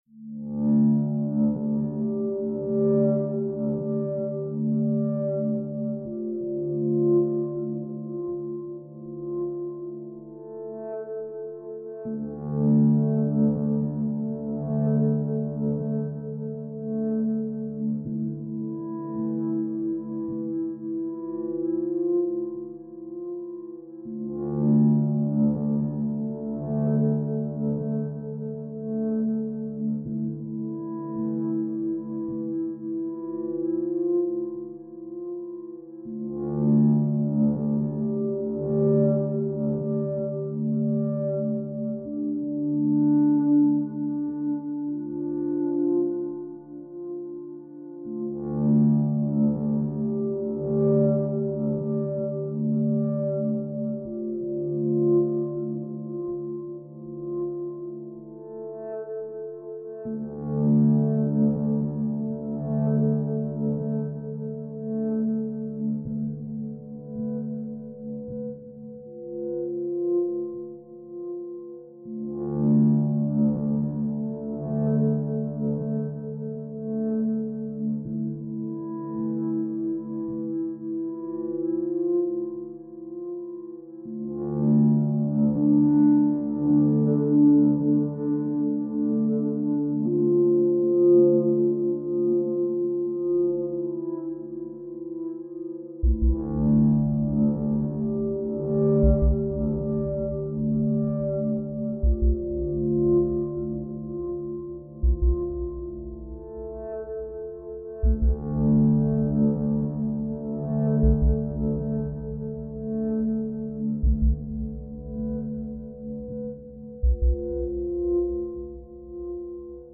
2007-2015 Электронная